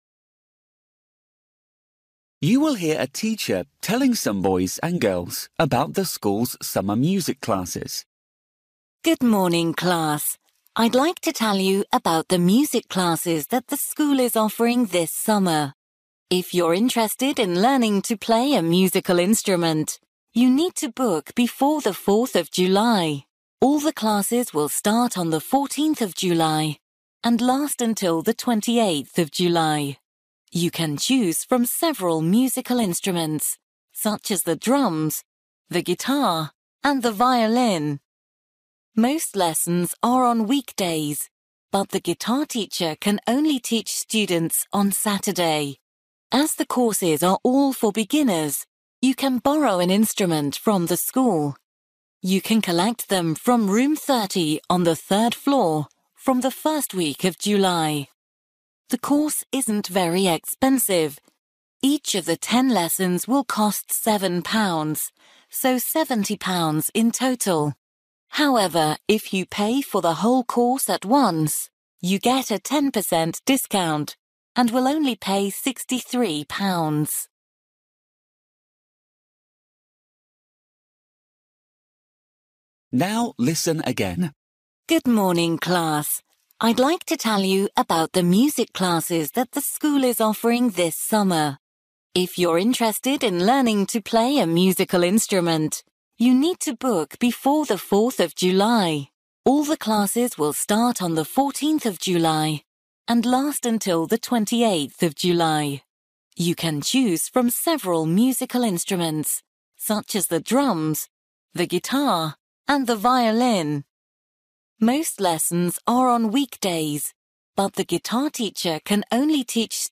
You will hear a teacher telling some boys and girls about the school’s summer music classes.